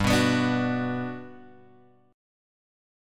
G Major 7th